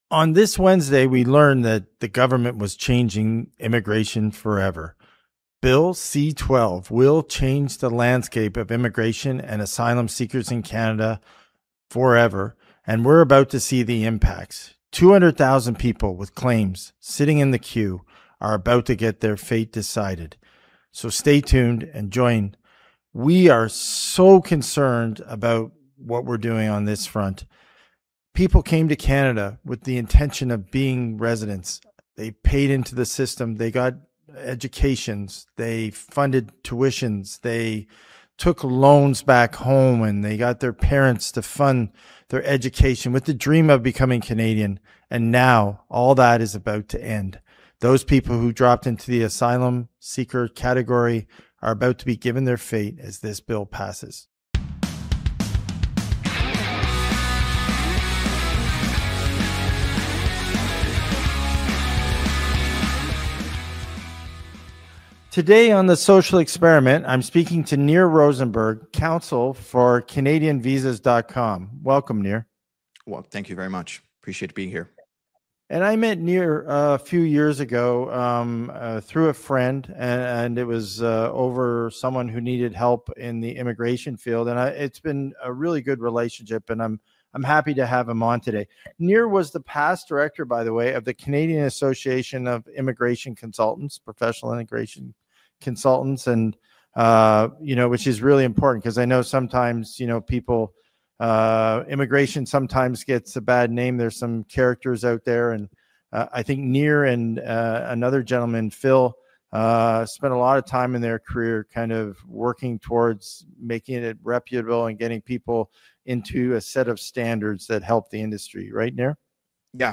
… continue reading 15 episodes # Politics # News Talk # News # True Patriot Love